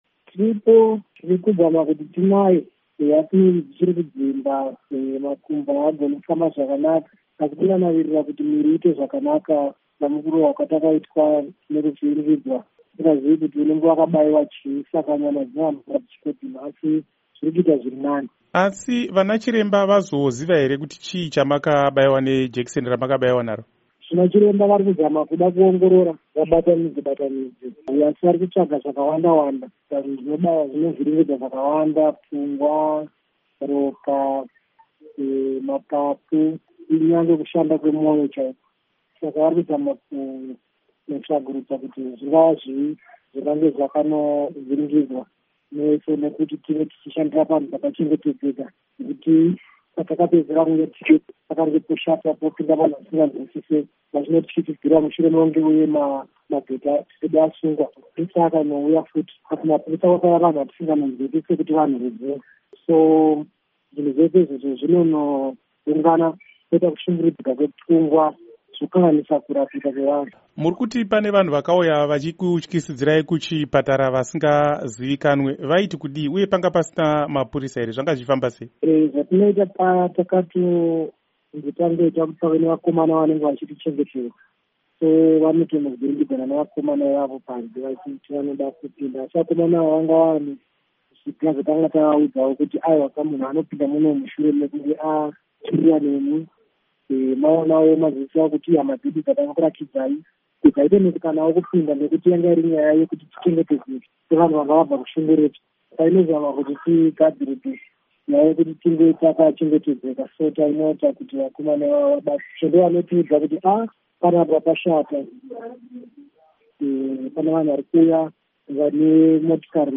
Hurukuro naVaWomberaiishe Nhende